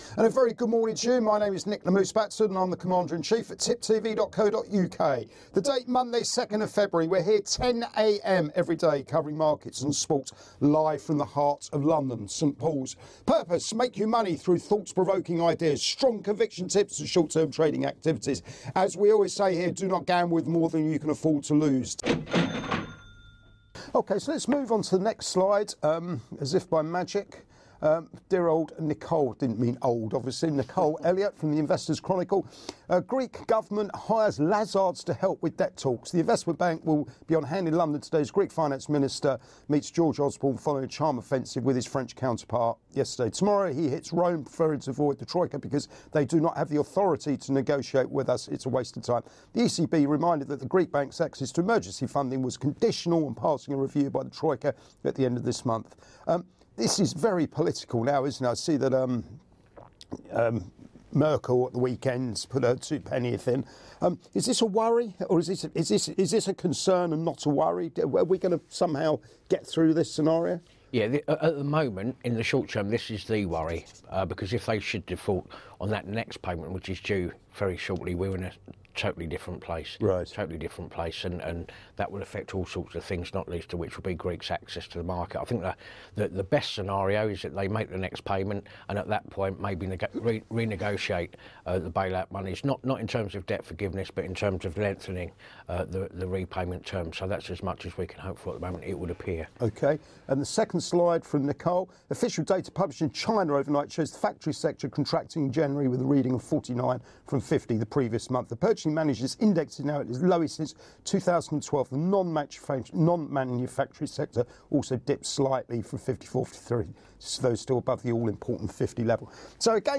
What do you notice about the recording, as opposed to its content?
Live Market Round-Up & Soapbox thoughts